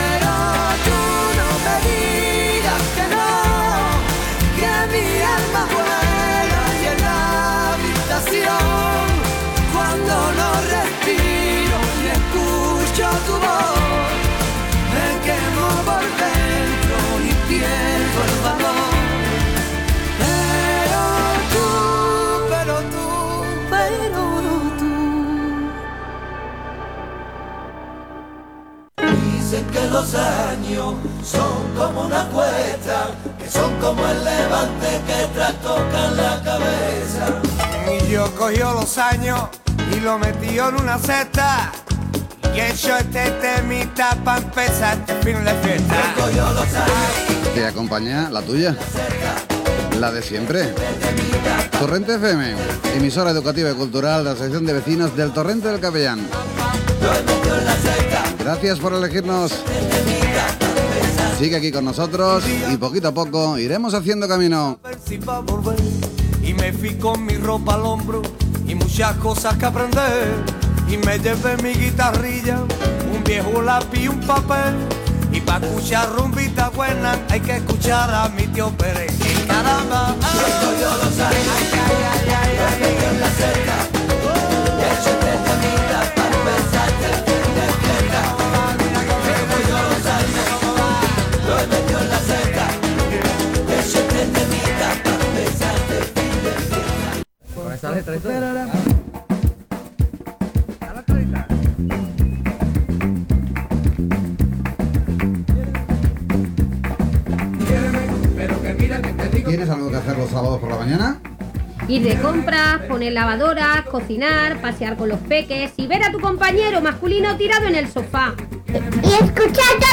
Tema musical, identificació de la ràdio